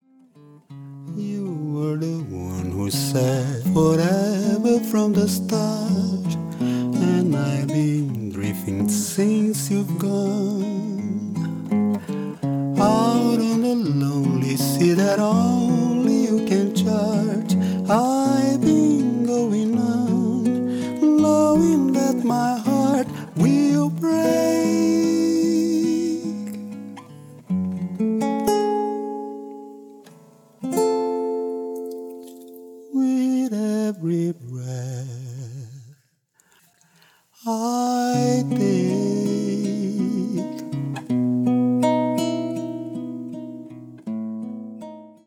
ギターと声だけ。
まろやかなその歌は円熟の極み。ギター弾き語りでジャズやブラジル音楽のスタンダードを歌う。
vo,g